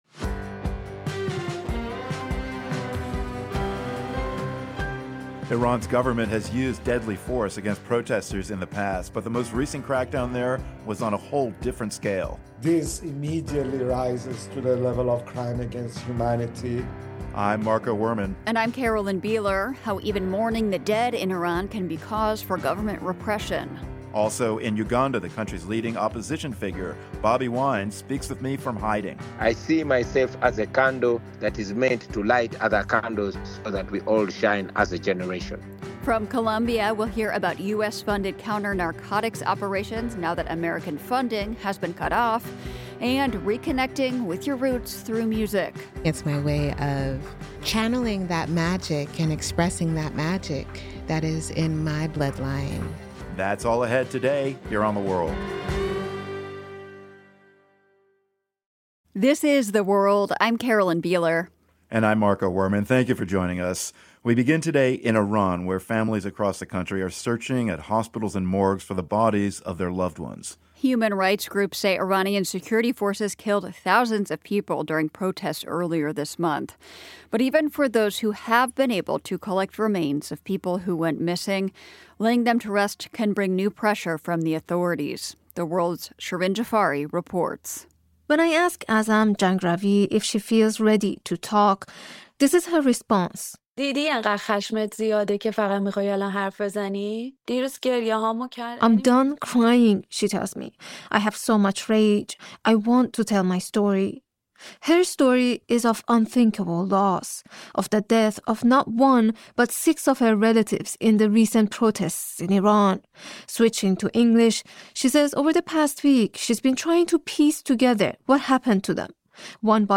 Among those who have found them, some say they have been intimidated and threatened by security forces for holding funerals. Also, Bobi Wine, the leading opposition figure in Uganda’s presidential elections, speaks to The World’s Marco Werman from hiding, one week after Uganda’s longtime president, Yoweri Museveni, was declared the winner of a disputed election.